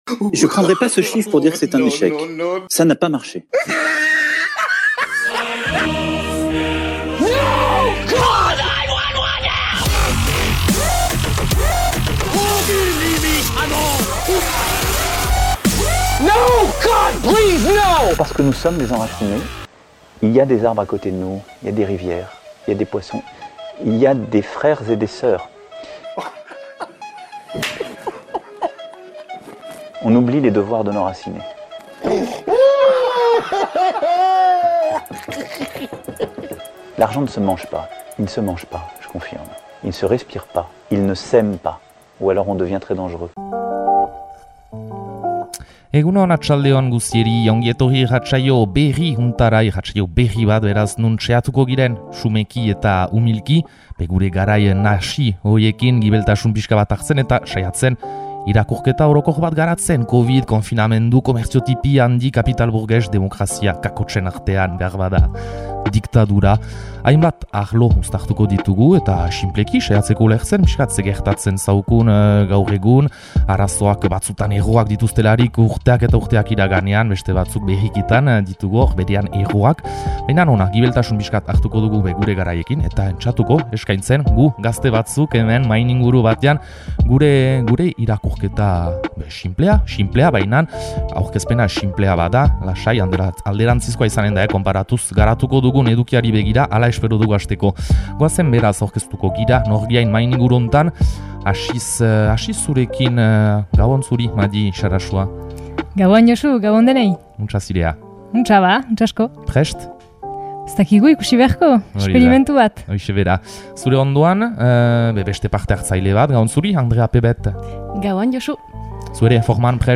ZANTZU Ipar Euskal Herriko gazte batzuek eramandako irratsaio berria duzue. Bere helburua? Umilki koiunturaren irakurketa sakon eta pentsatu bat eramaitea eta plazaratzea, eharrezkoa den gibeltasuna hartzen entseatuz! Covid, konfinamendu, kapital, burgesia, gazte, neska, antolakuntza, “demokrazia” (?)… Horiek dira entzuten ahalko dituzuen hitzak Zantzu emankizunean!